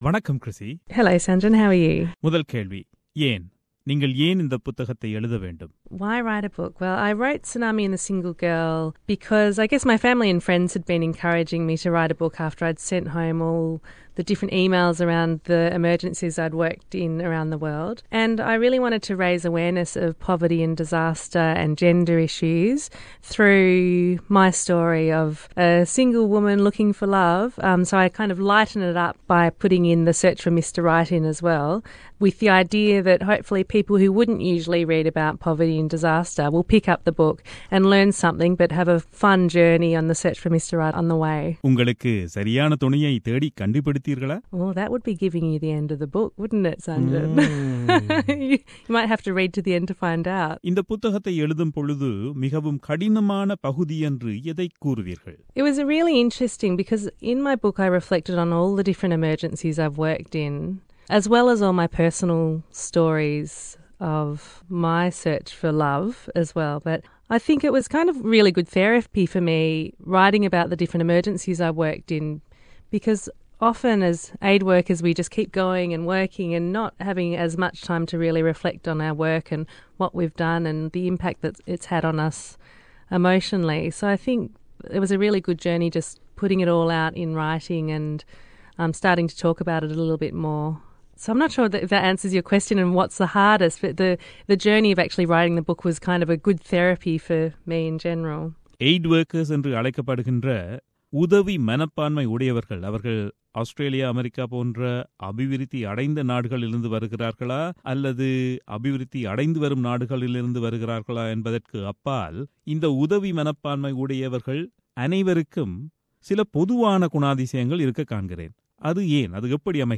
சந்தித்து உரையாடுகிறார்